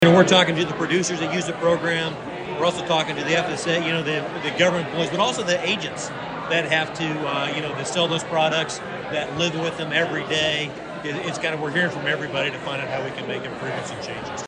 Following the discussion, Congressman Mann spoke with reporters about the need for increasing crop insurance to bolster the safety net for farmers.